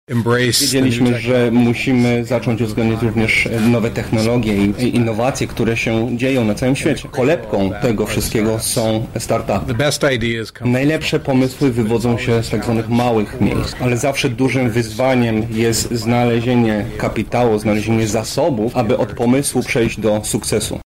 Podstawą tego porozumienia są start-upy i inne małe biznesy. O tym jak ważne jest ich wspieranie mówił gubernator Nevady Brian Sandoval: